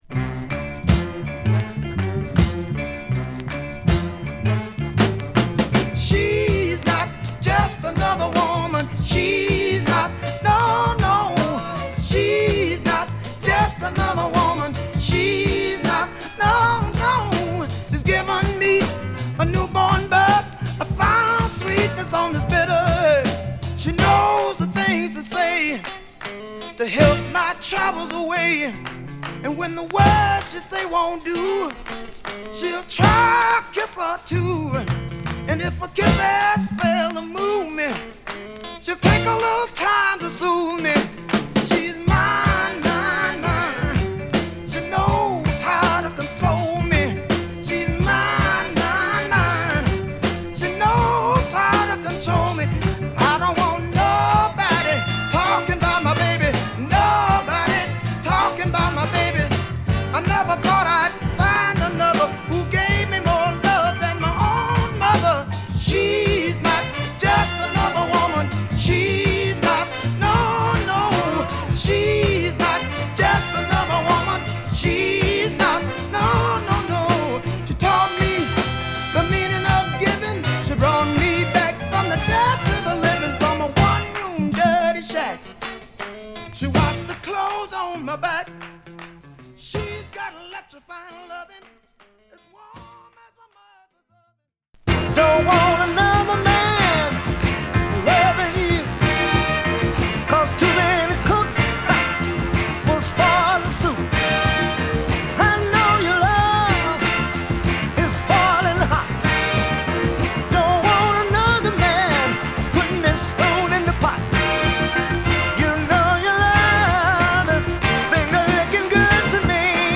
＊盤面擦れ多し／針飛び無し